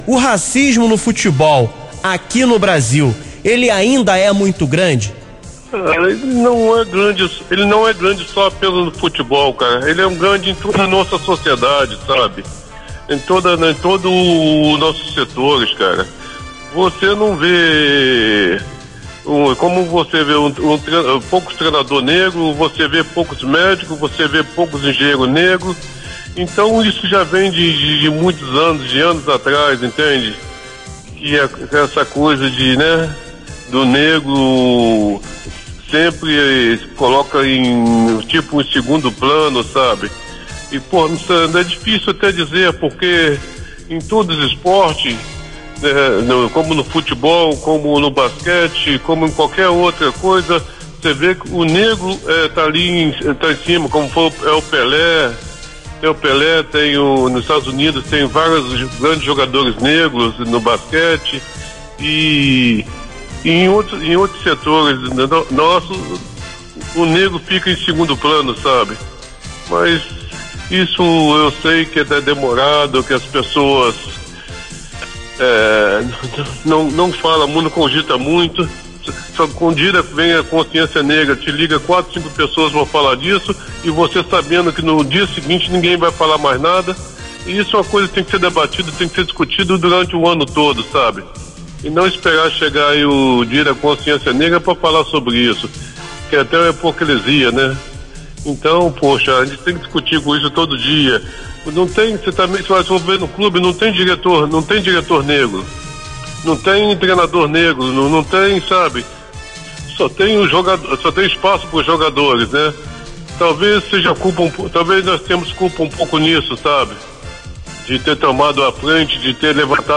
Em participação no Show da Galera deste sábado, Andrade (áudio acima), ídolo do Flamengo, falou sobre como o preconceito racial interfere no futebol e no esporte em geral.